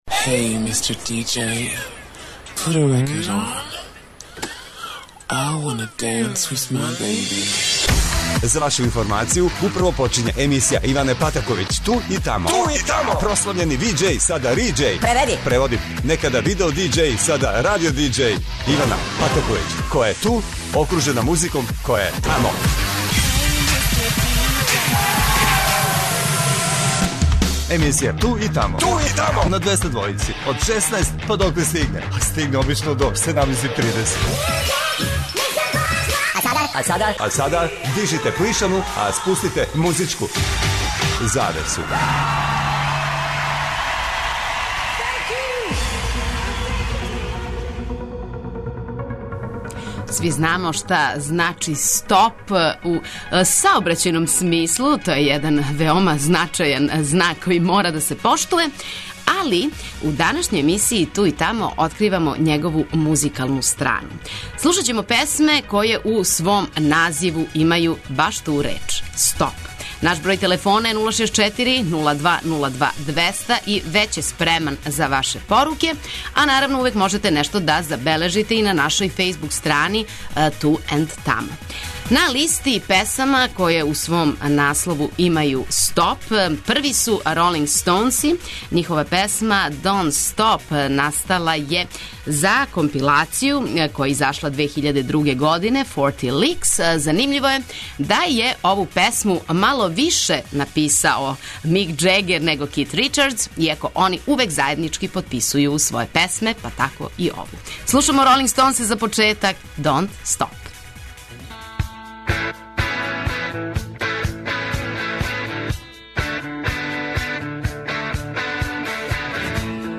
Његово музичко значење откривамо у новој емисији "Ту и тамо" на Двестадвојци што значи да ћемо слушати само песме које у свом наслову имају реч "стоп".